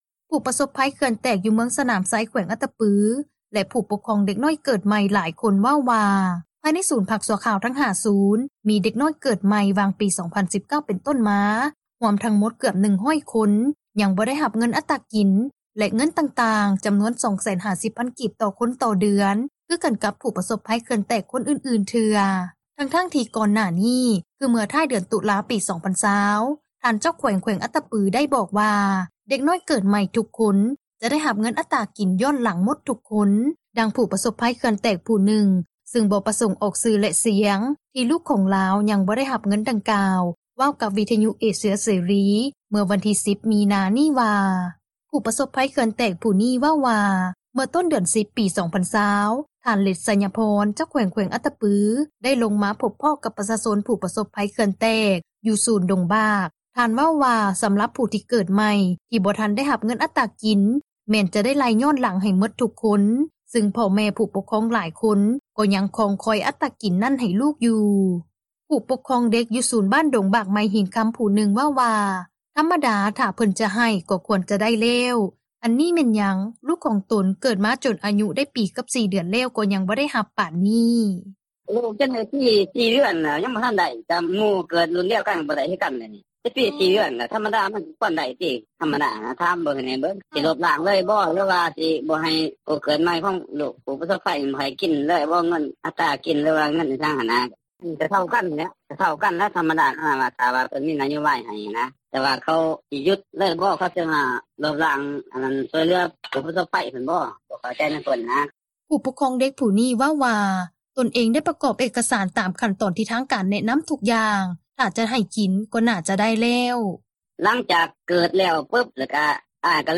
ນັກຂ່າວພົລເມືອງ